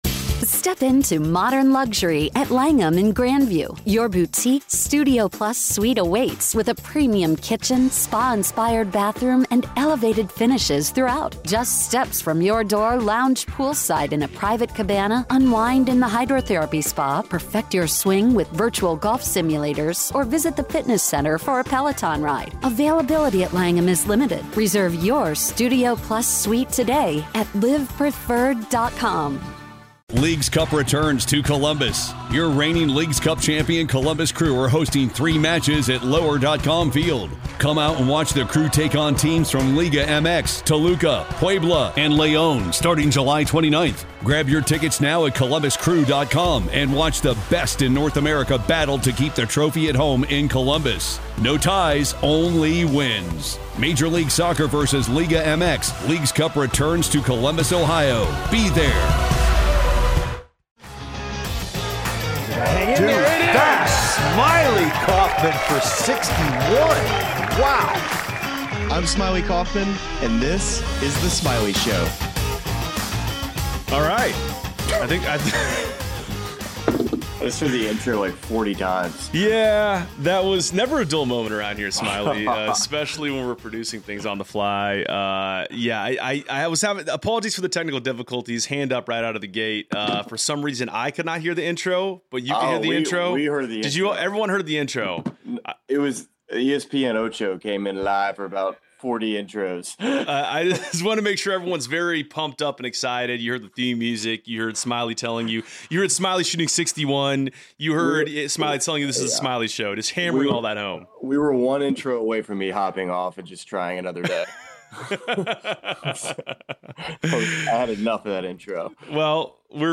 Live Show: 124th U.S. Open Recap